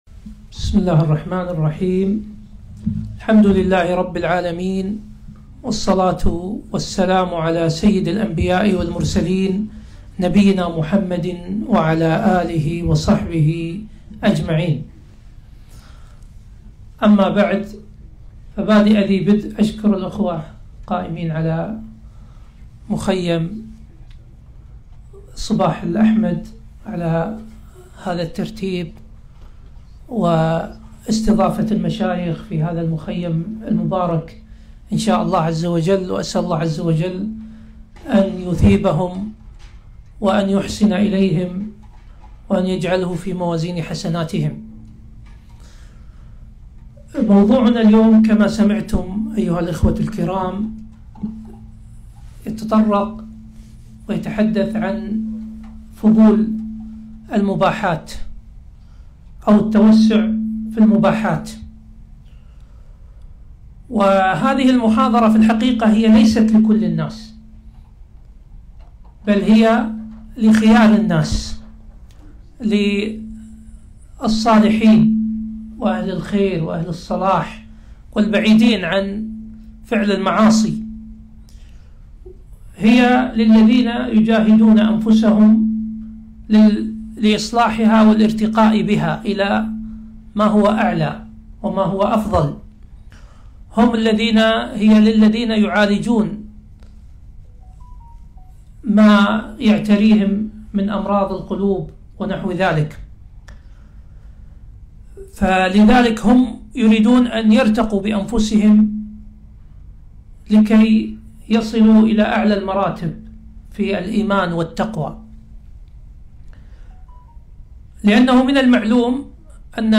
محاضرة - التوسع في المباحات وعواقبه